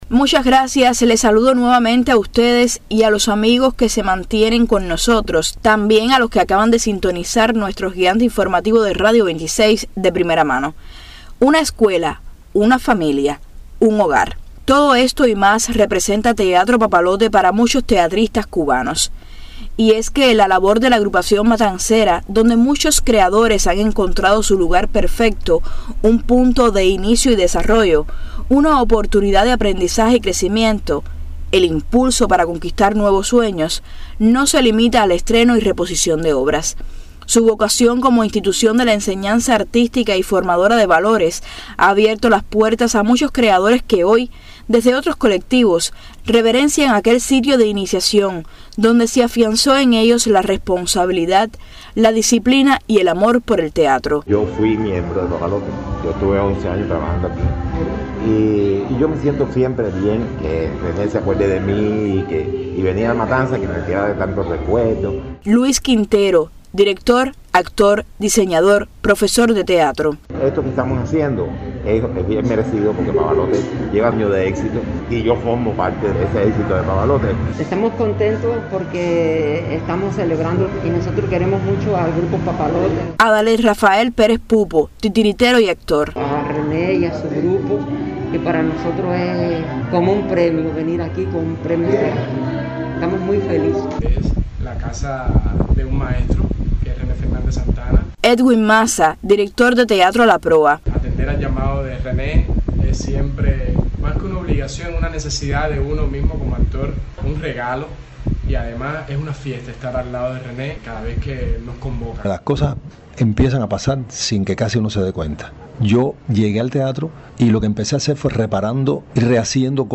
3er-trabajo-reportaje-artistas-que-han-estado-en-papalote.mp3